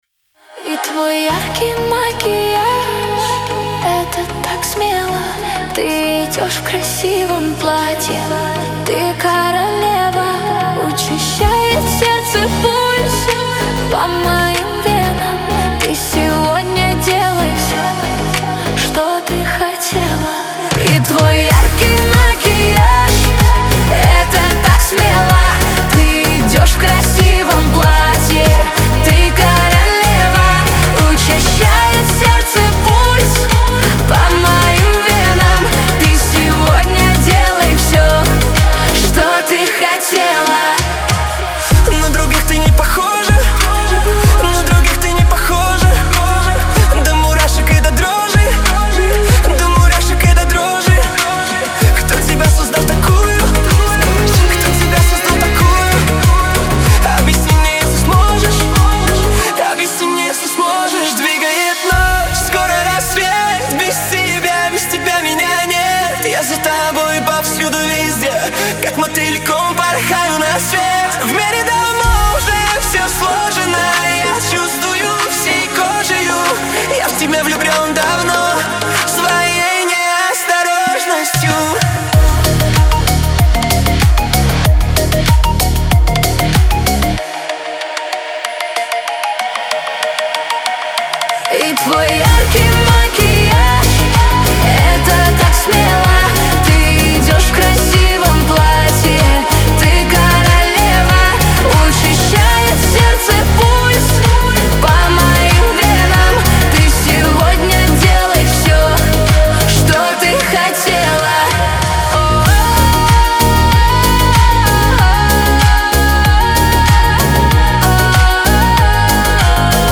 pop , диско
дуэт
эстрада